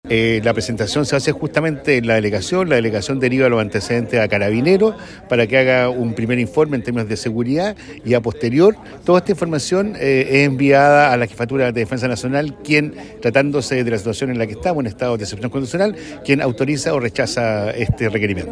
Desde el Gobierno, el delegado Presidencial de La Araucanía, Eduardo Abdala, indicó que carabineros debe realizar un informe de pre factibilidad y que debe ser la jefatura de la Defensa Nacional quien debe autorizar el espectáculo.